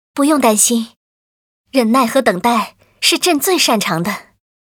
文件 文件历史 文件用途 全域文件用途 Erze_fw_04.ogg （Ogg Vorbis声音文件，长度4.7秒，97 kbps，文件大小：56 KB） 源地址:地下城与勇士游戏语音 文件历史 点击某个日期/时间查看对应时刻的文件。